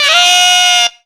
HARSH SQUEAL.wav